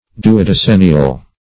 Search Result for " duodecennial" : The Collaborative International Dictionary of English v.0.48: Duodecennial \Du`o*de*cen"ni*al\, a. [L. duodecennis; duodecim twelve + annus year.] Consisting of twelve years.